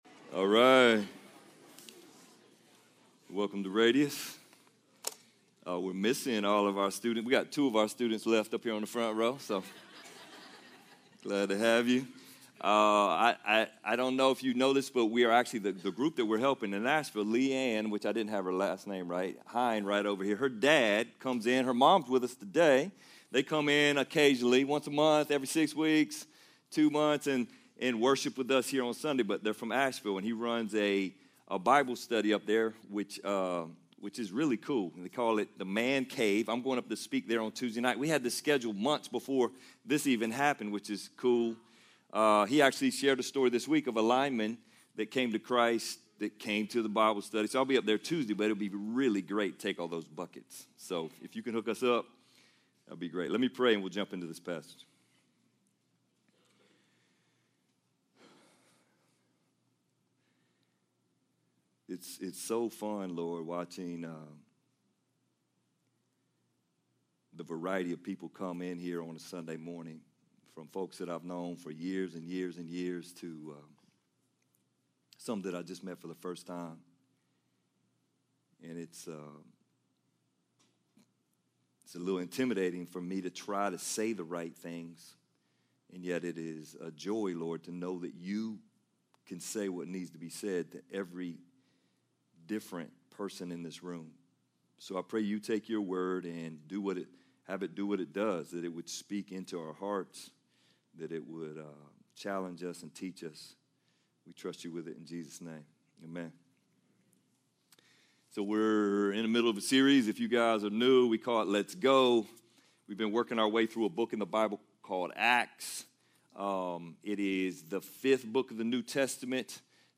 Sermon Library | RADIUS Church